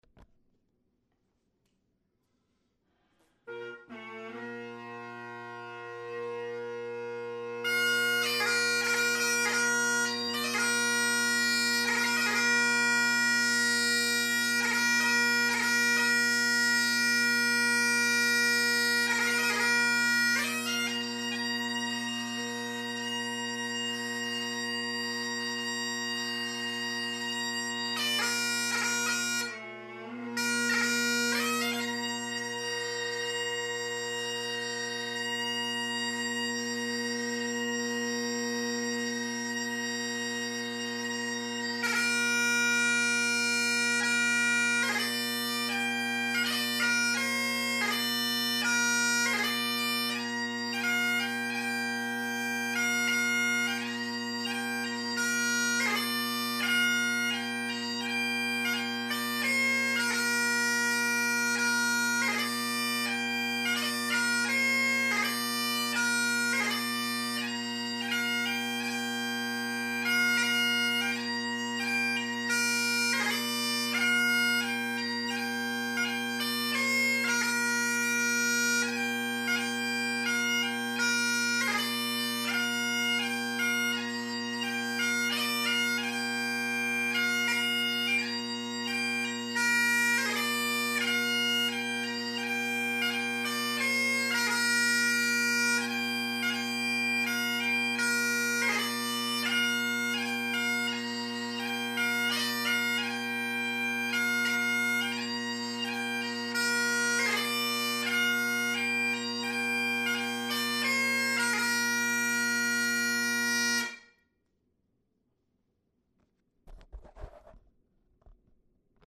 one from the 50’s and the other from the 70’s. Both played with exactly the same setup (Ezee tenors and original Kinnaird bass). Interesting the different sounds emitted from the same maker, just different time periods.
50’s sinclair_Ezeedrone tennors_Kinnaird bass
50s-sinclair_Ezeedrone-tennors_Kinnaird-bass.mp3